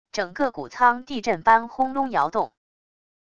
整个谷仓地震般轰隆摇动wav音频